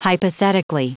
Prononciation du mot hypothetically en anglais (fichier audio)
Prononciation du mot : hypothetically